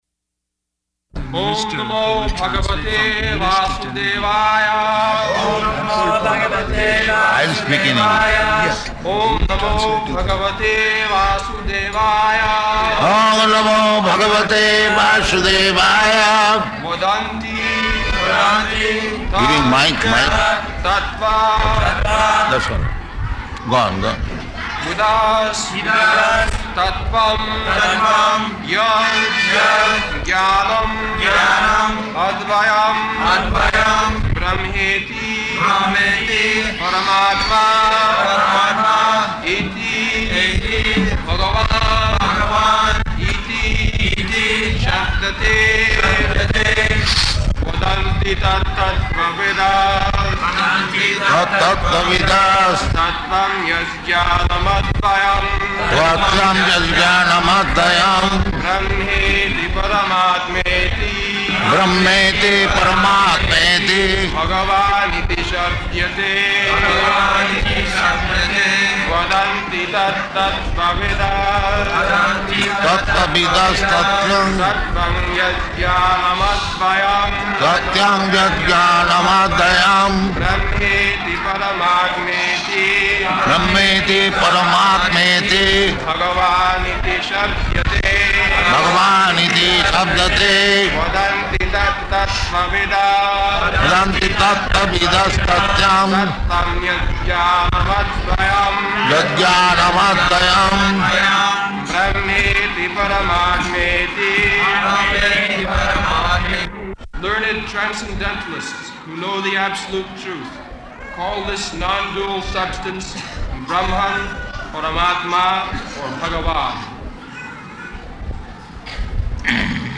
April 26th 1974 Location: Tirupati Audio file
[Prabhupāda and devotees repeat] [leads chanting of verse] [Prabhupāda having discussion with devotee in background = "I will speak in English.